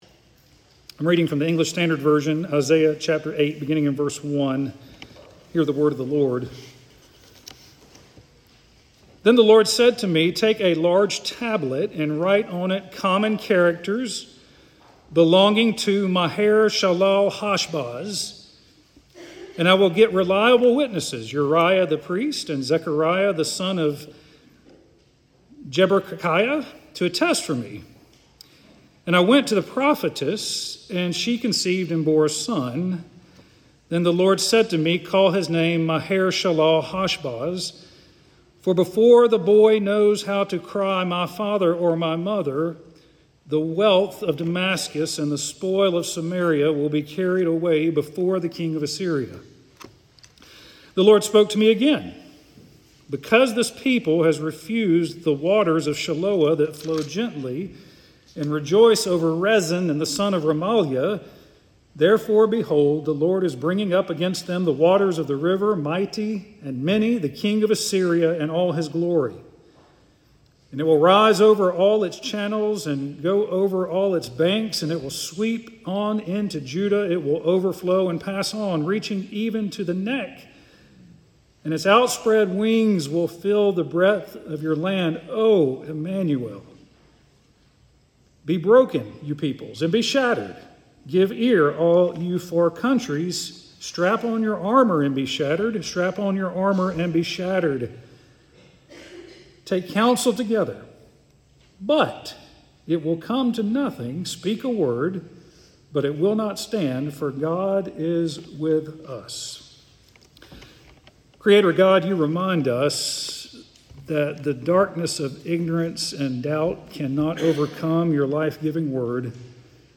Immanuel Sermon
Listen to Immanuel sermon published on Nov 23, 2025, by Trinity Presbyterian Church in Opelika, Alabama.